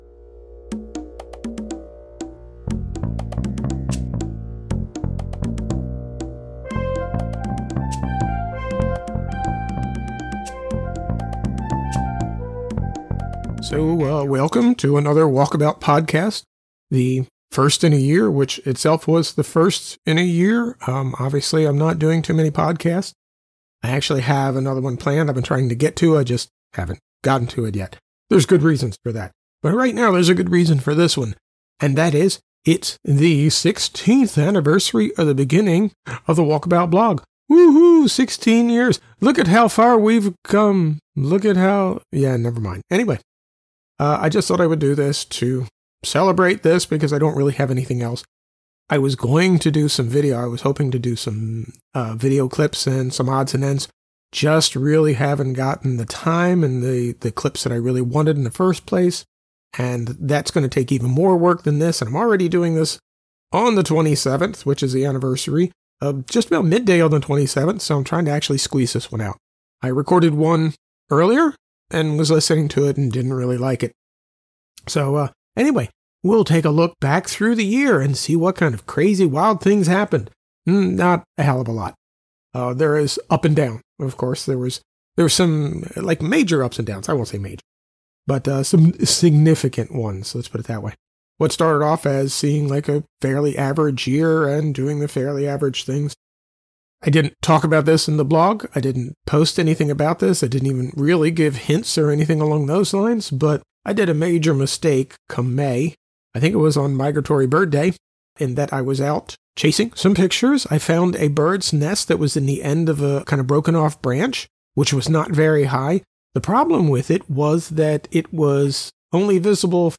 In mid 2016 I started again with a different approach, mostly unscripted, and though the mic was better this time, I didn’t get a truly good one until November 2016, so from June through September they’re a little rough – but still interesting.